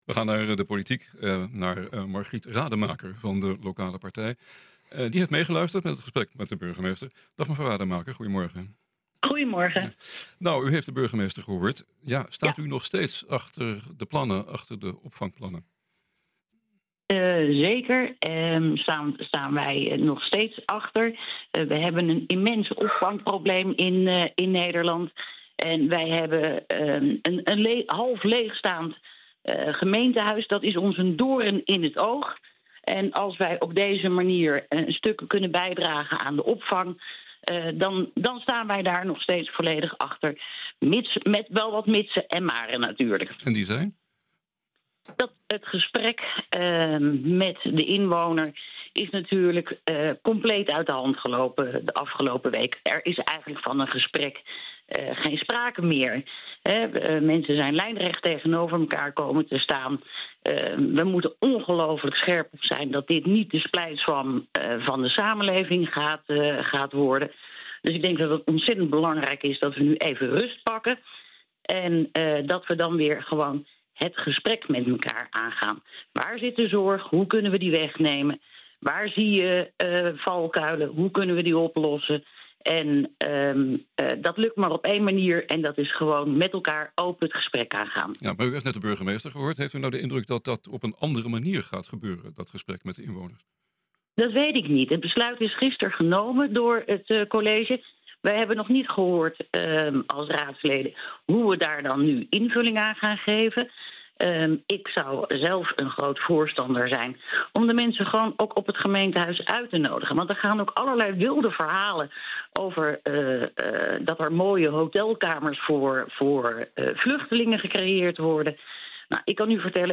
Gisteravond heeft de gemeente besloten om van 110 naar 70 opvangplekken te gaan. Wij spreken de fractievoorzitter van de grootste partij in Wijdemeren: Margriet Rademaker van De Lokale Partij.